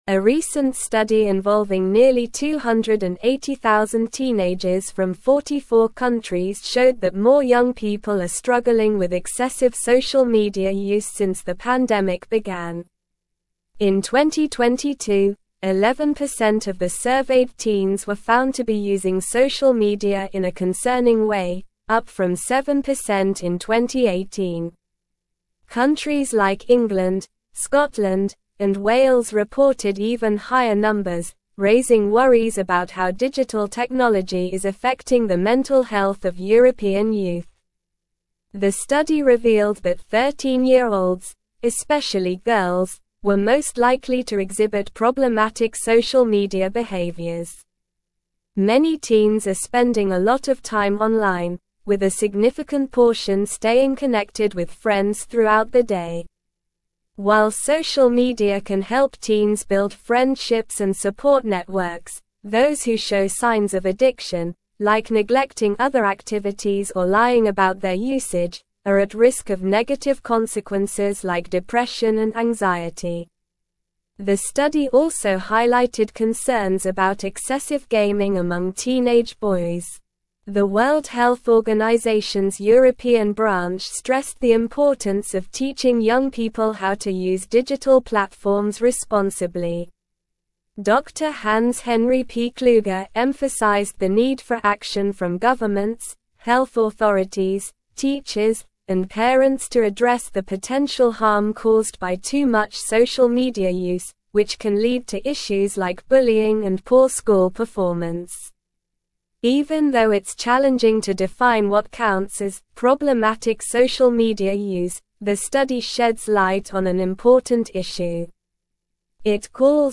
Slow
English-Newsroom-Upper-Intermediate-SLOW-Reading-Concerning-Increase-in-Teenage-Social-Media-Use-Detected.mp3